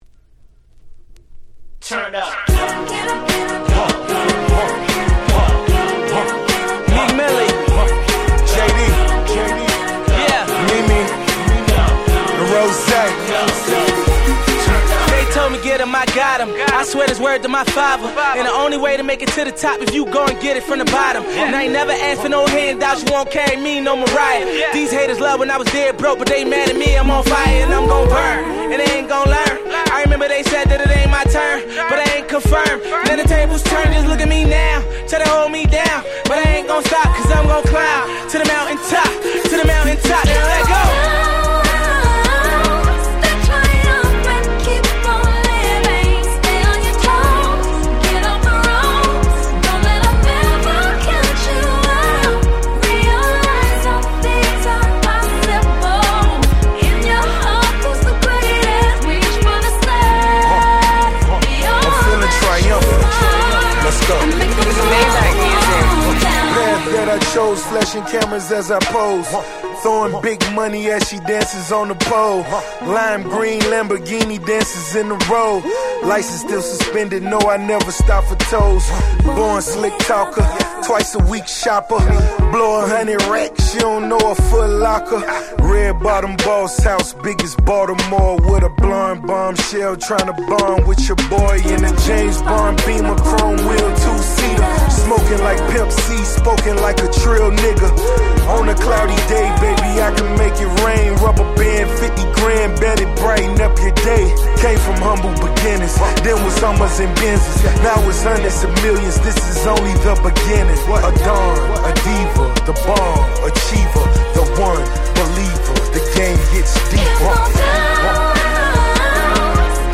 12' Smash Hit R&B !!